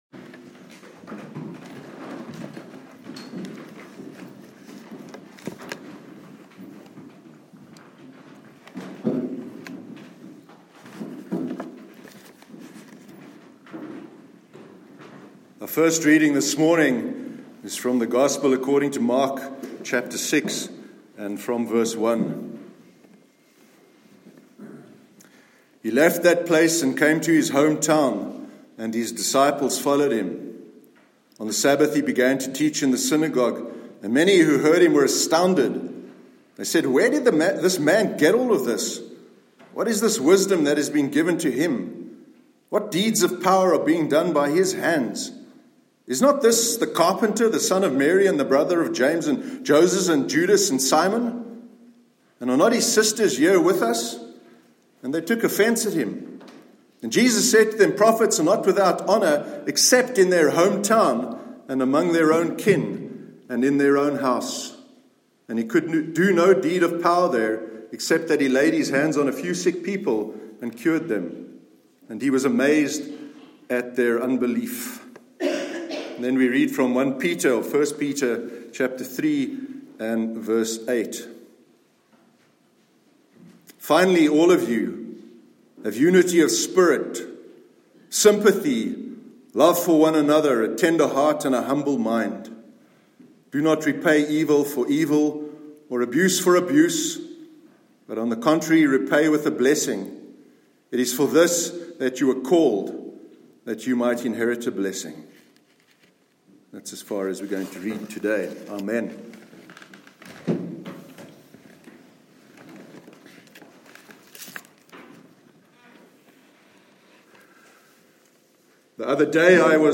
Sermon on Meaningful Relationships and Dunbar’s Number- 16th September 2018